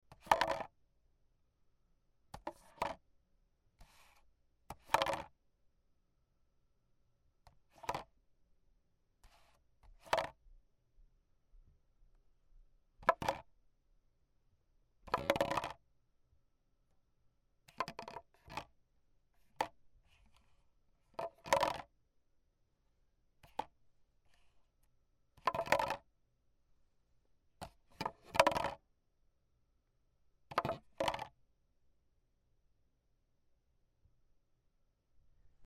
ペットボトルに触れる
/ F｜演出・アニメ・心理 / F-21 ｜Move 移動_動作